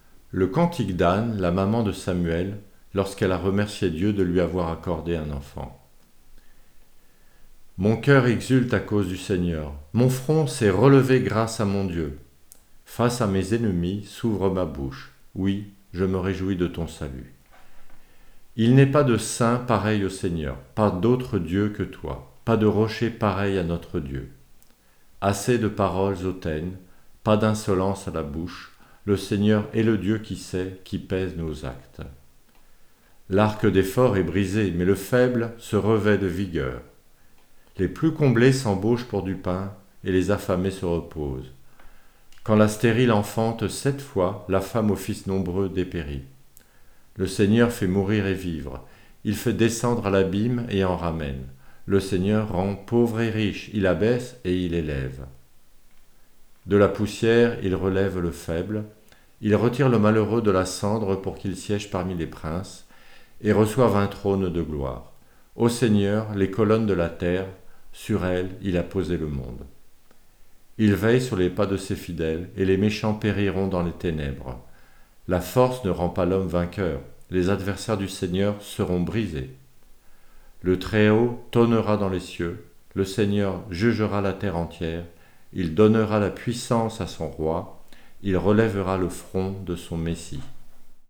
Prière